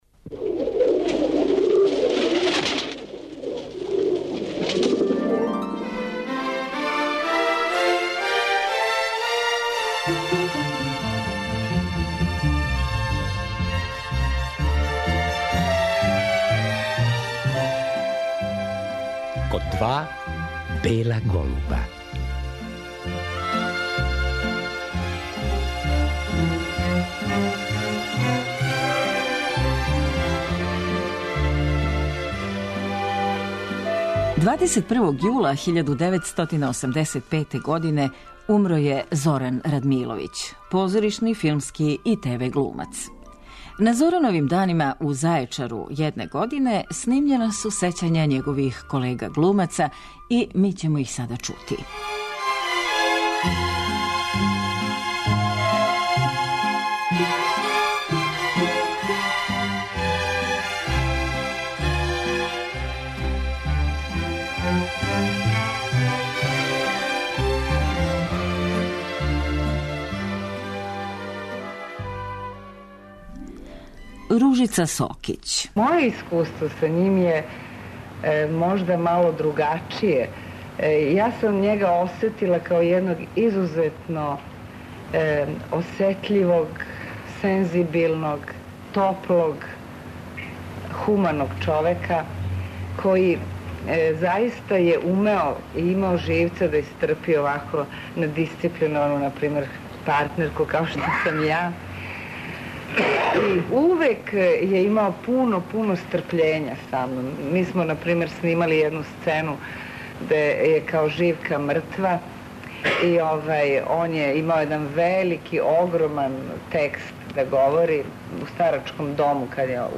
Поводом годишњице смрти Зорана Радмиловића, 21. јул 1985. године, чућемо снимак са вечери сећања на популарног глумца одржане 1988. године у оквиру Дана Зорана Радмиловића у Зајечару.
О свом колеги говорили су Мира Бањац, Данило Бата Стојковић, Ружица Сокић, Милан Цаци Михаиловић, Ерол Кадић, Аљоша Вучковић и други.